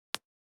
447リップクリーム,口紅,ふたを開ける,
効果音